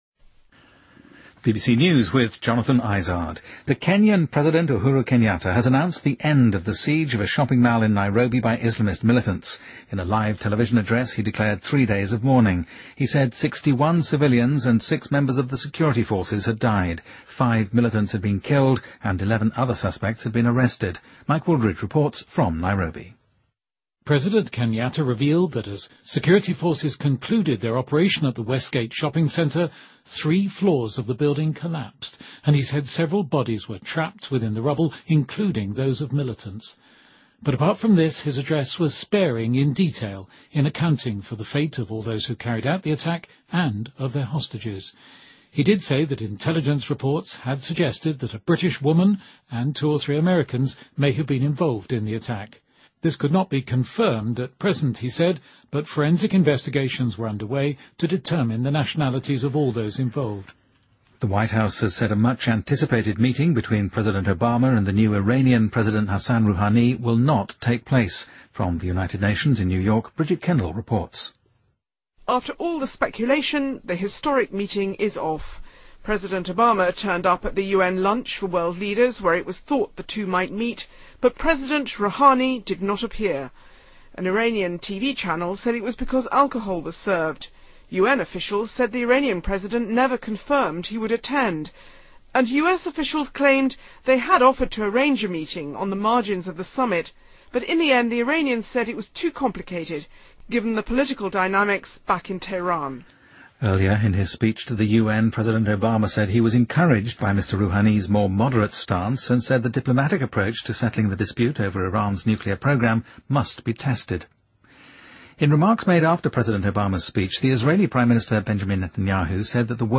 Date:2013-09-25Source:BBC Editor:BBC News